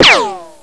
Ricochet.wav